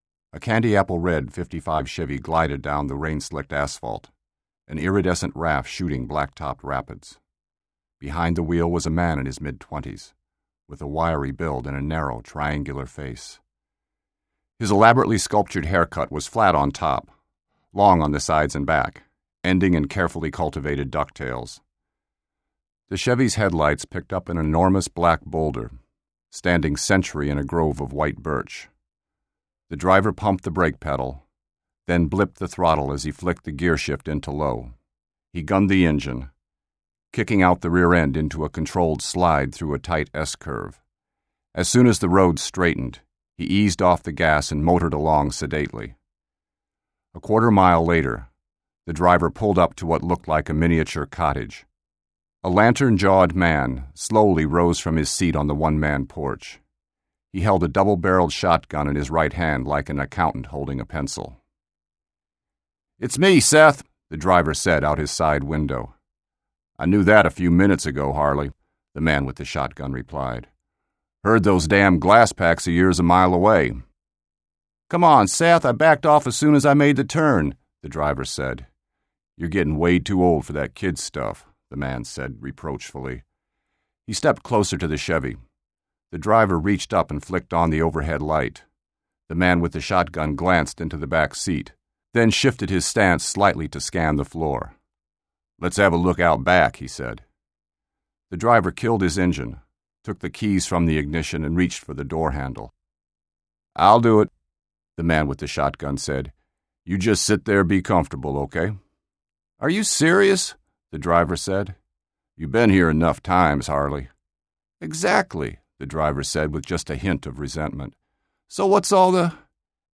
Two Trains Running Audiobook Excerpt